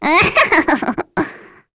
Nia-Vox_Happy3.wav